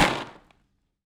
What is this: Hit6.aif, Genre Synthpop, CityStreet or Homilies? Hit6.aif